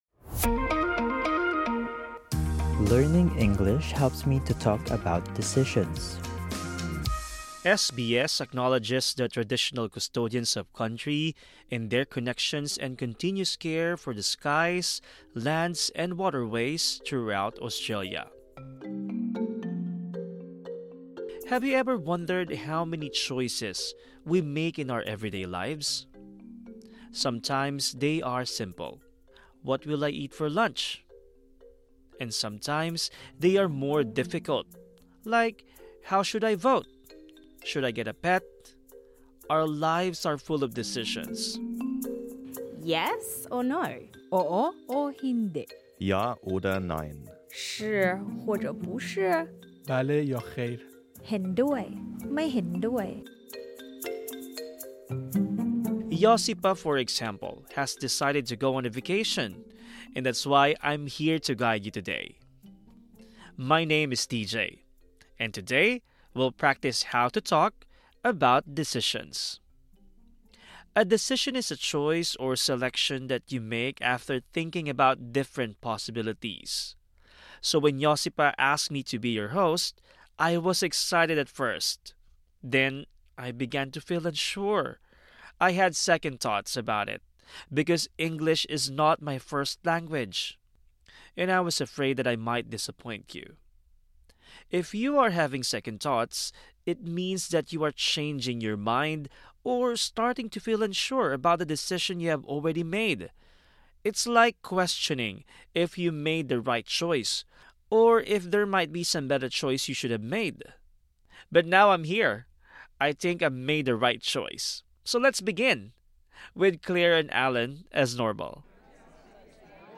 Learn how to talk about easy and difficult decisions. Plus, hear migrants discuss their decisions to move to Australia.